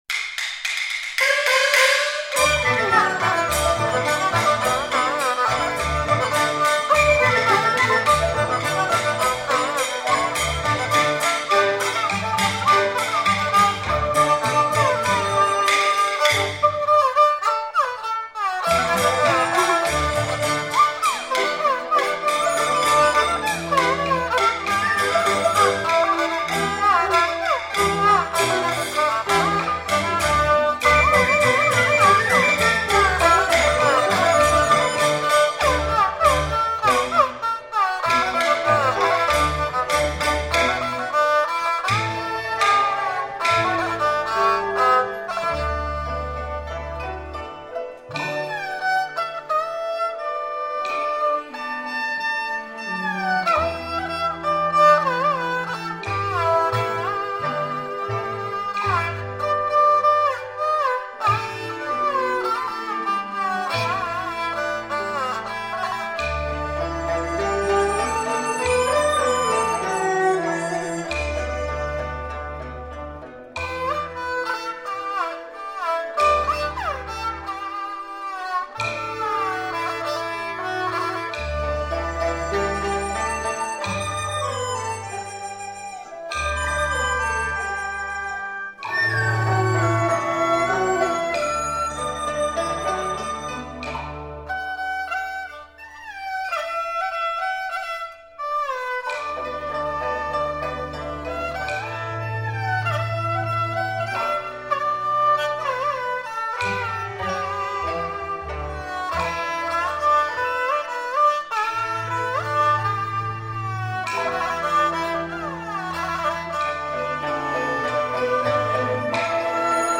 坠胡
坠胡是河南省颇为流行的一种弓弦乐器，为民间曲艺和戏曲伴奏，音域宽广，音色明亮，演奏起来既有歌唱性又富于模仿性。
这些作品演奏起来热情、华丽、幽默风趣，既有浓郁的乡土风味，又有生动活泼的生活气息。